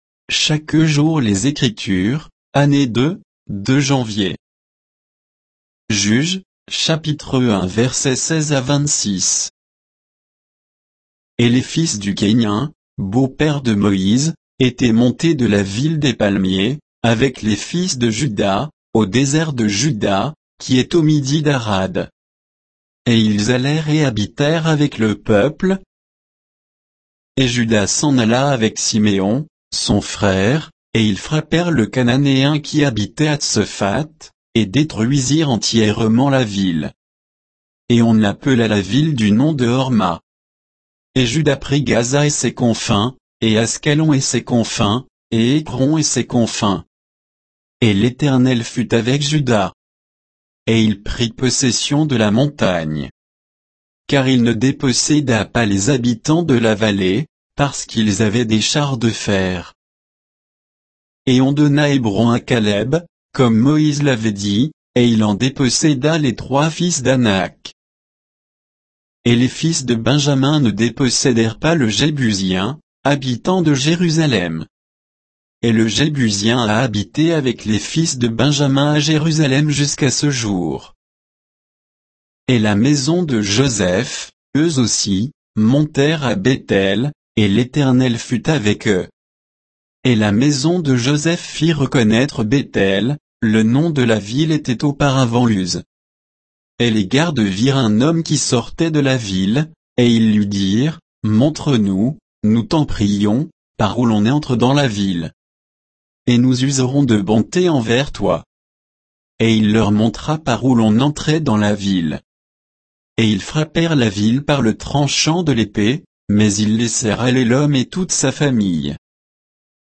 Méditation quoditienne de Chaque jour les Écritures sur Juges 1, 16 à 26